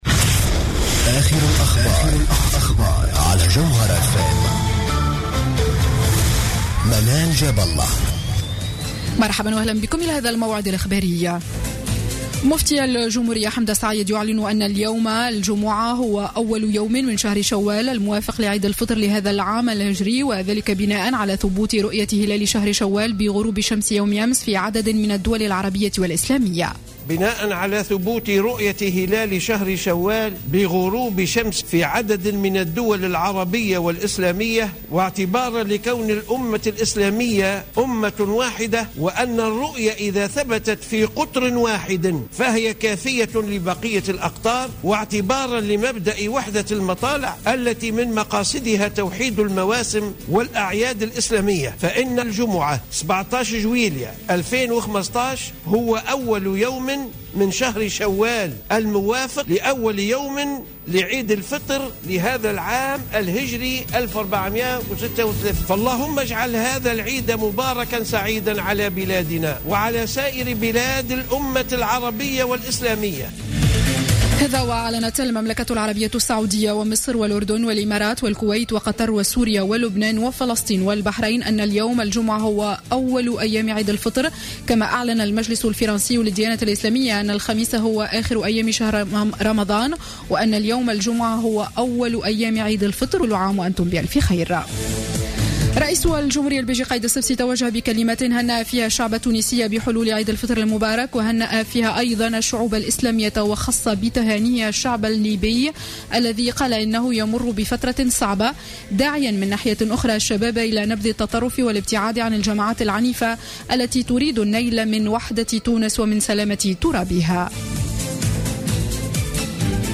Journal Info 00:00 du Vendredi 17 Juillet 2015